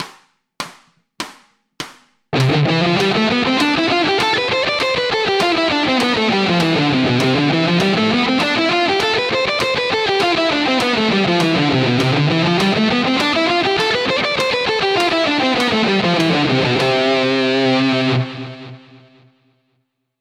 Первая гамма – до мажор.
C-D-E-F---G-A-B-C---D-E-F-G---A-B-C-D---C-B-A-G---F-E-D-C---B-A-G-F---E-D-C-B. После этого гамму можно повторить и не один раз.
Аудио (100 УВМ)